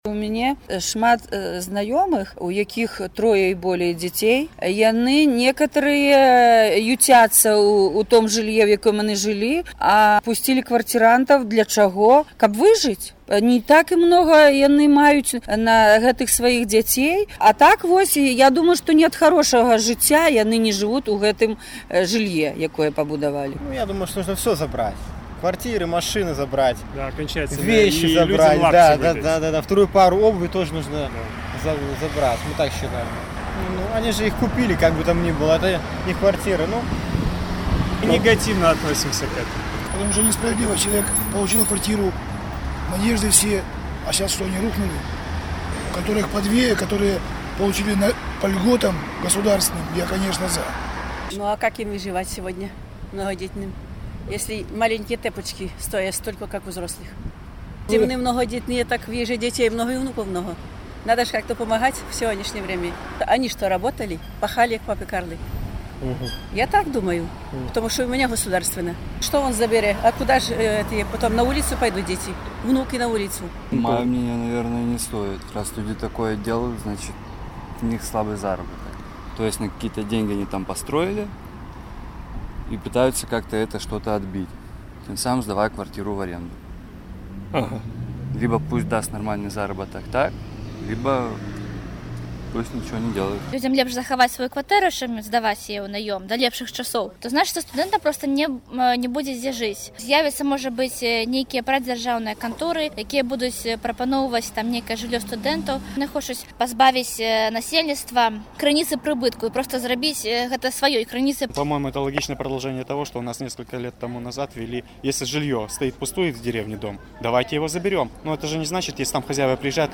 На такое пытаньне карэспандэнта «Свабоды» адказвалі сёньня мінакі на вуліцах Берасьця.